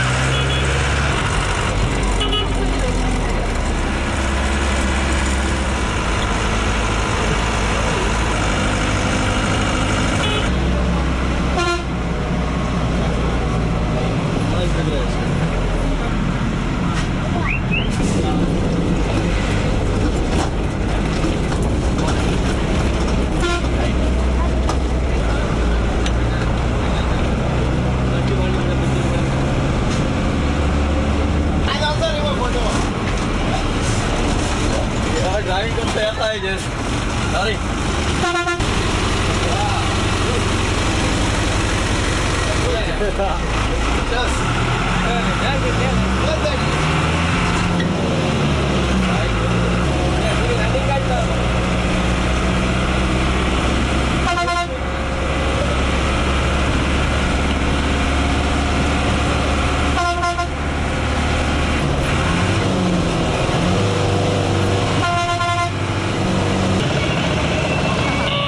普利克运输公司烂尾三 " evenmorebusnoise
标签： 氛围 汽车 公共汽车 发动机 现场记录 公共交通
声道立体声